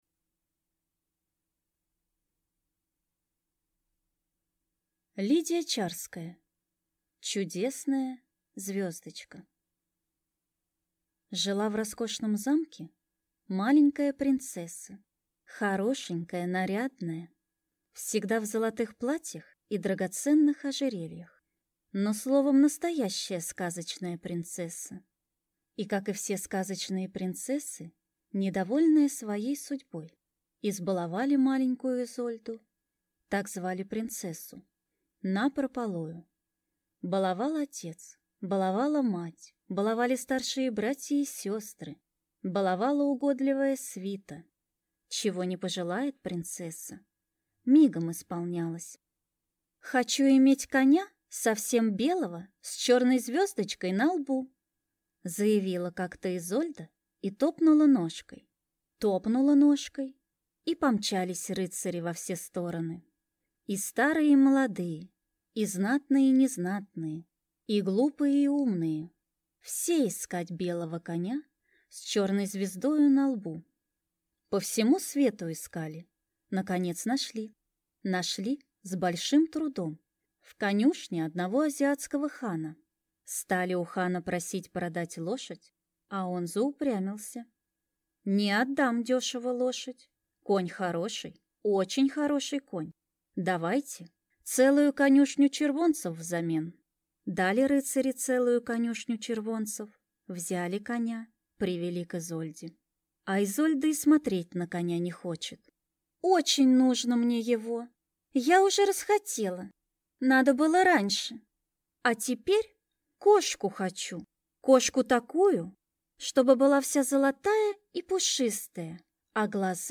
Аудиокнига Чудесная звездочка | Библиотека аудиокниг